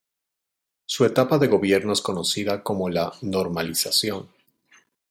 /ɡoˈbjeɾno/